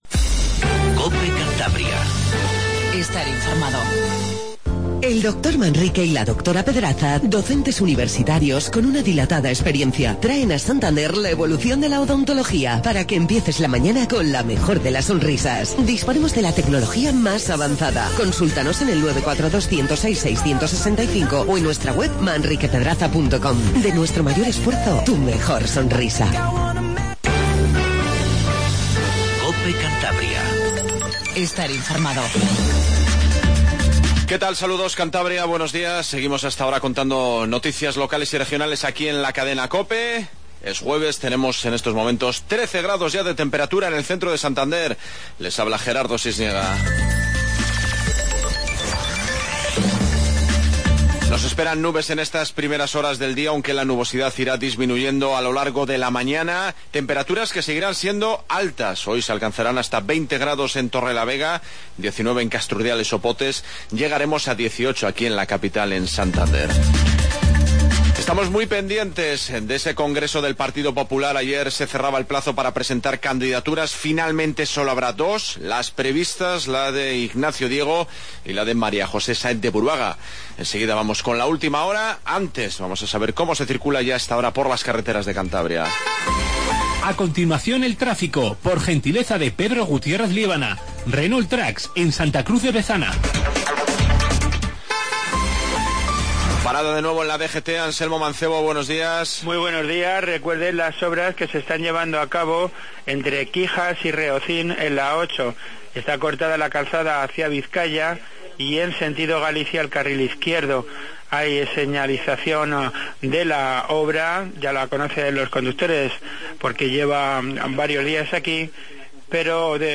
INFORMATIVO MATINAL 07:50